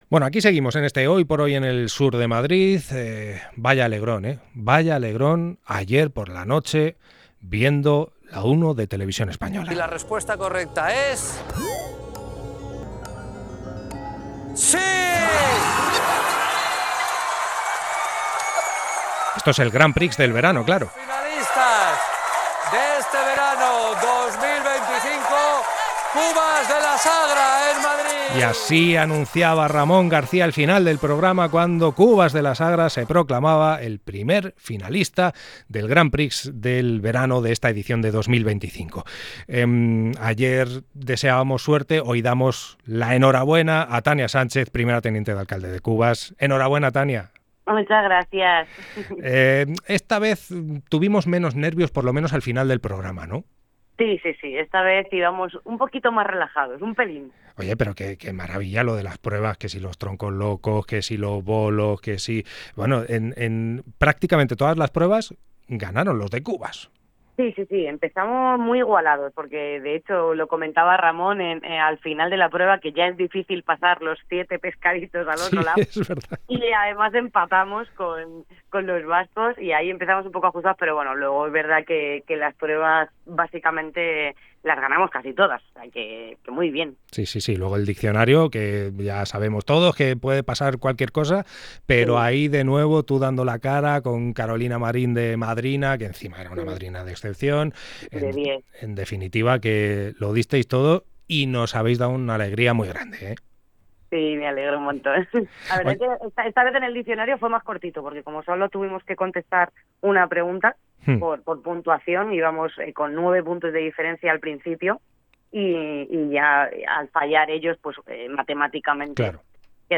Entrevista con Tania Sánchez, primera teniente de alcalde de Cubas de la Sagra, tras su paso a la semifinal del Grand Prix del verano